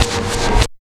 81 NOISE  -R.wav